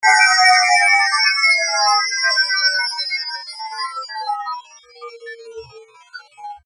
Gemafreie Sounds: Sweeps und Swells
mf_SE-2070-digital_liquid_sweep.mp3